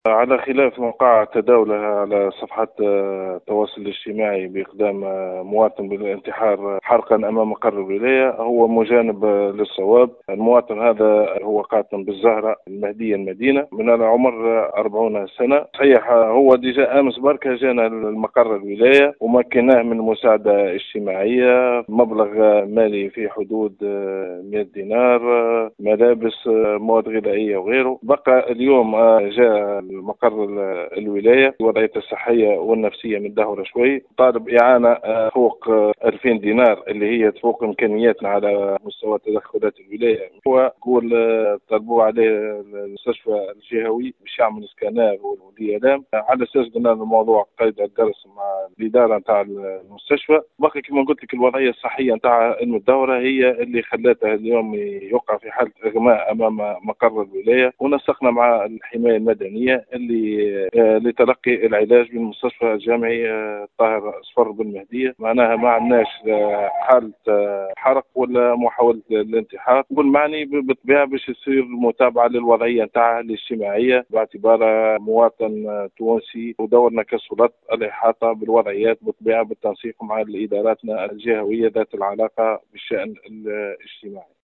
نفى المعتمد الأول لولاية المهدية عادل بن عمر في تصريح ” ل ام اف ام” ماتم ترويجه في مواقع التواصل حول إقدام موطن أصيل الجهة على الانتحار حرقا أمام الولاية.